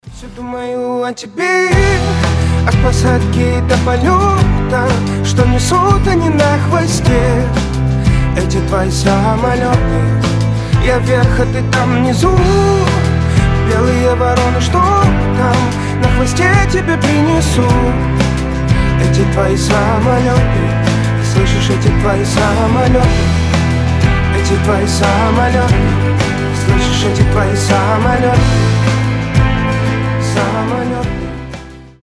Романтические рингтоны
Мужской голос , Поп